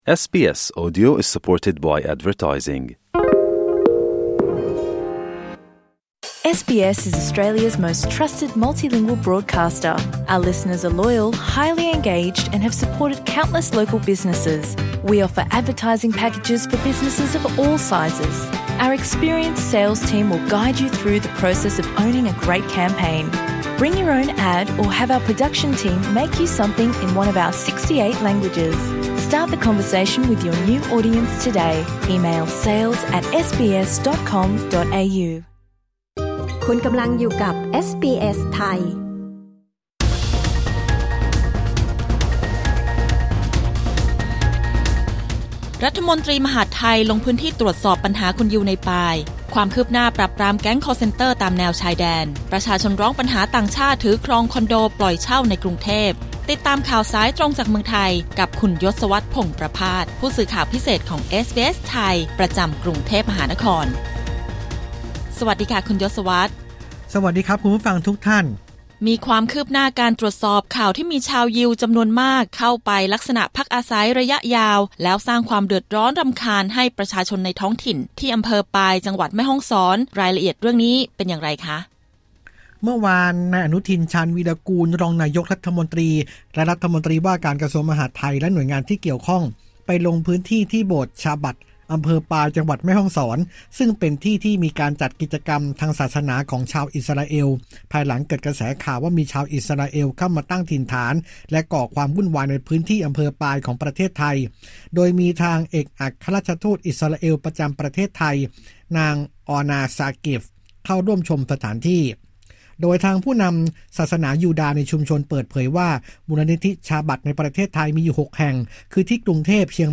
กด ▶ ฟังรายงานข่าวด้านบน